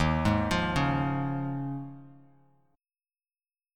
Eb9sus4 chord